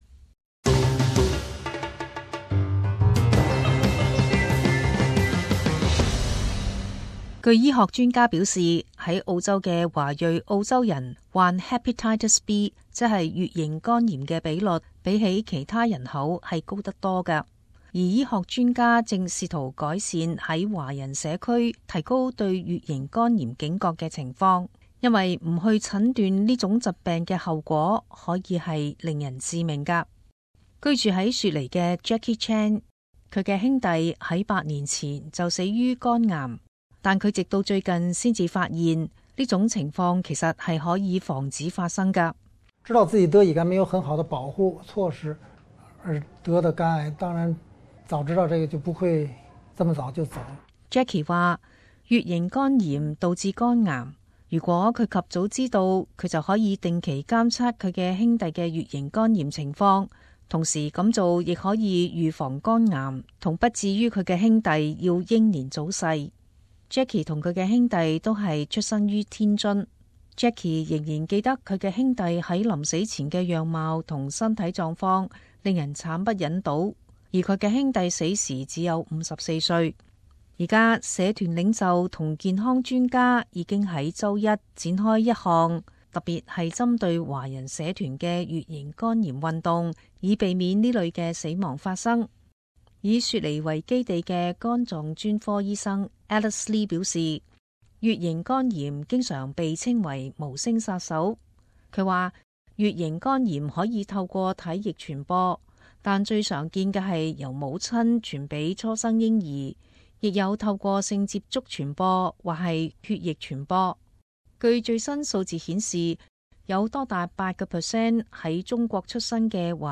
【時事報導】澳洲華人患乙型肝炎比率增高